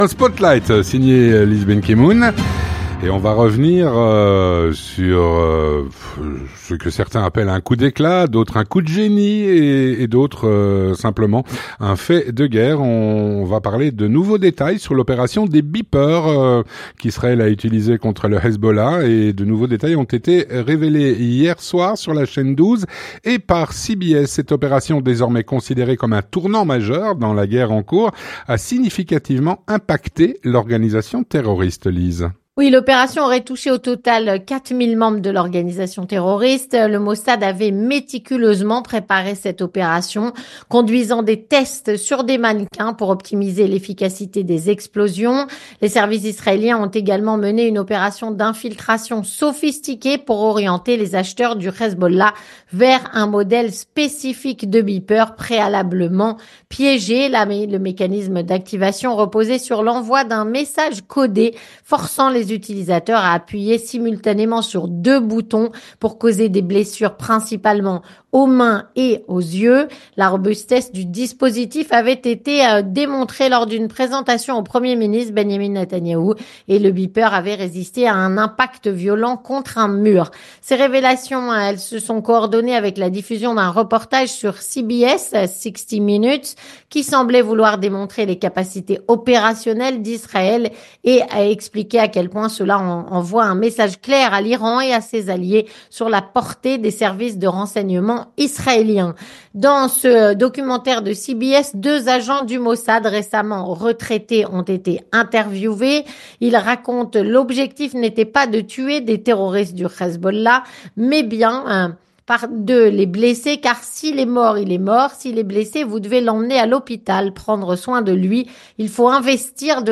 Une chronique